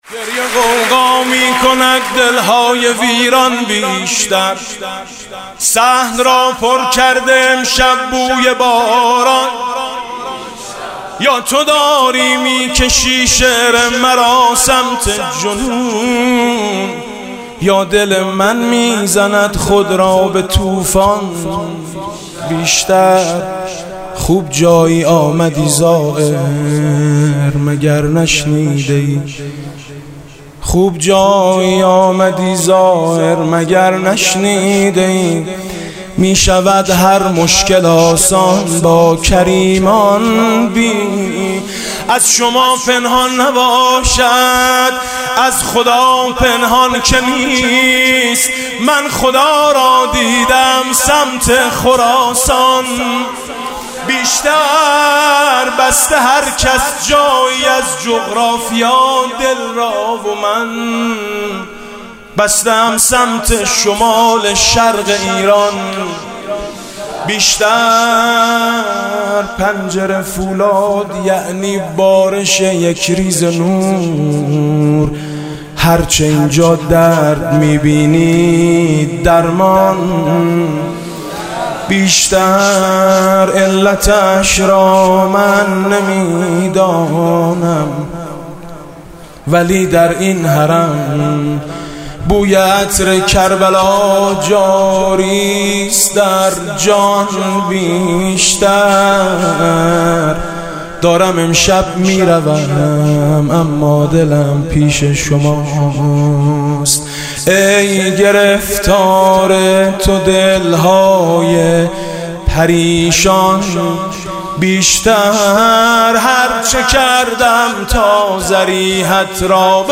«میلاد امام رضا 1393» مدح: من خدا را دیده ام سمت خراسان بیشتر